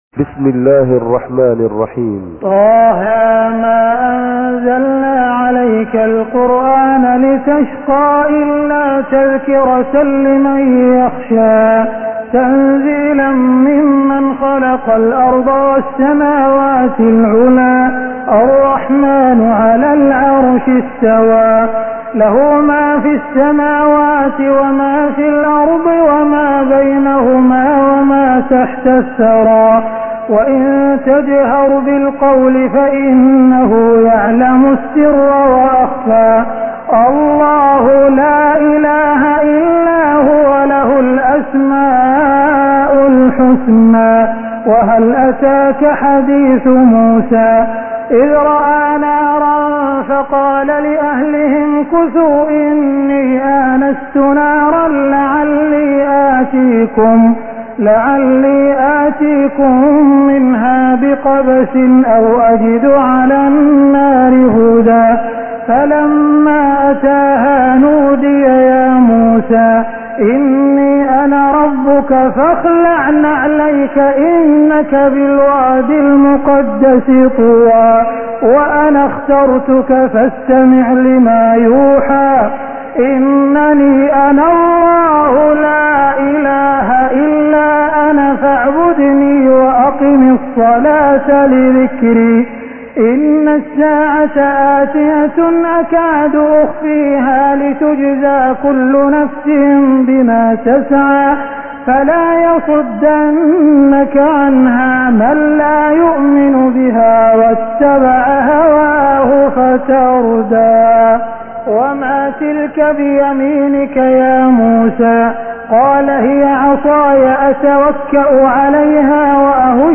تراويح الليلة الخامسة عشر رمضان 1420هـ سورة طه كاملة Taraweeh 15 st night Ramadan 1420H from Surah Taa-Haa > تراويح الحرم المكي عام 1420 🕋 > التراويح - تلاوات الحرمين